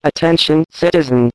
scanner
spch_attention3.ogg